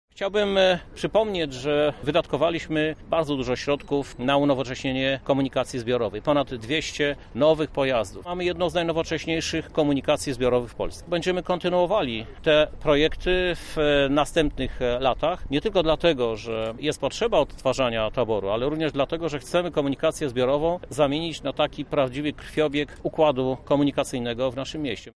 żuk kontrakt– przypomina obecny prezydent Lublina.